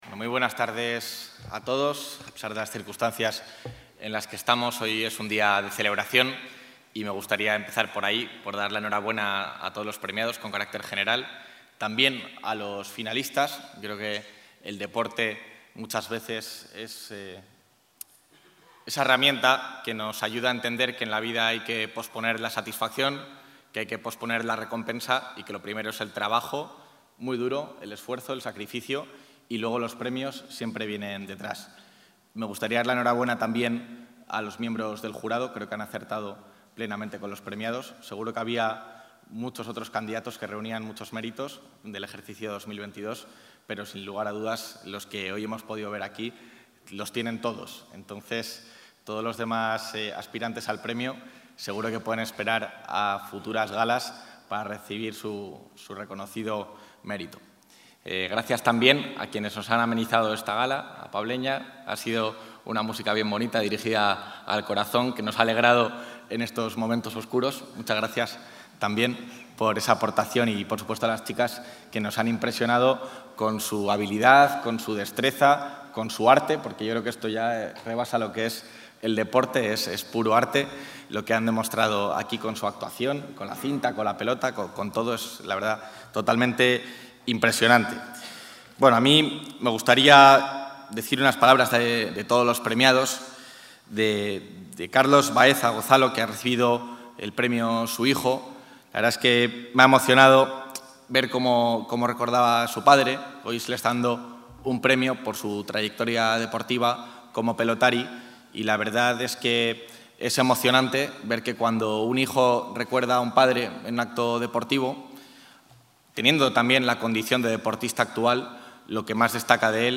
Intervención del vicepresidente de la Junta.